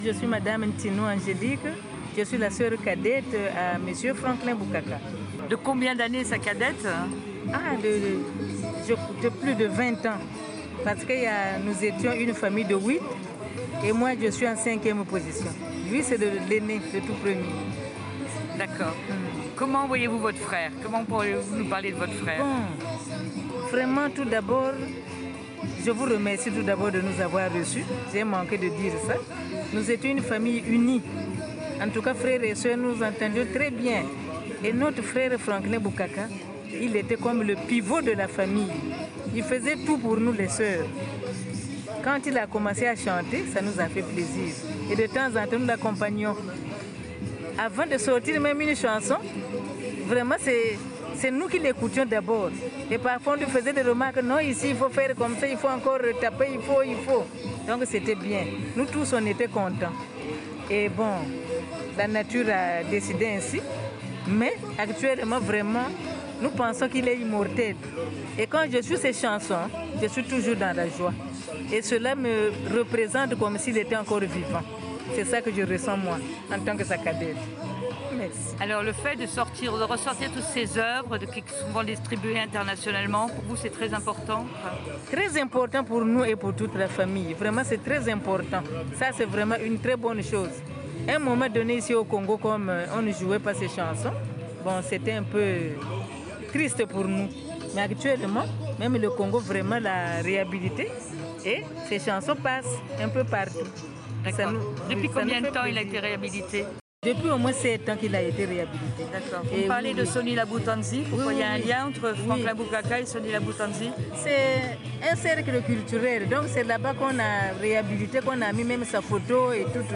Pour découvrir l’homme qu’il fut pour sa famille et son quartier de Bakongo, écoutez ce témoignage familial passionnant.